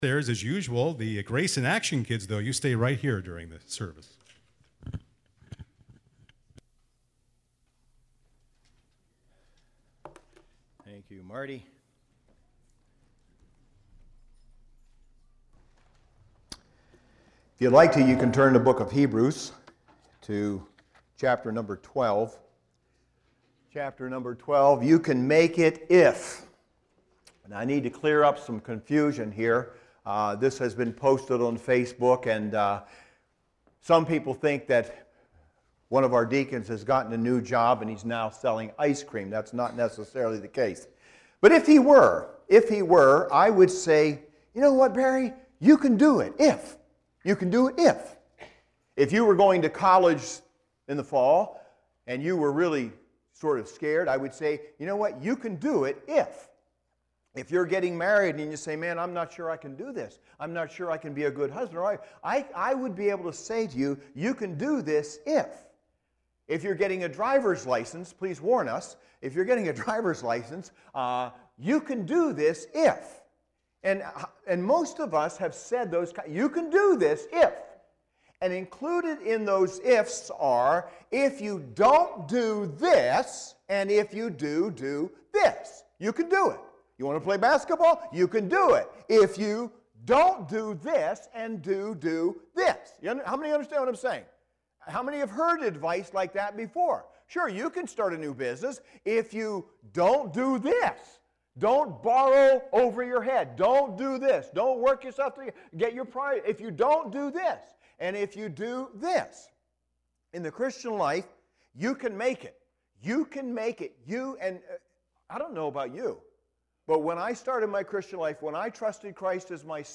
SERMONS | Grace Fellowship Church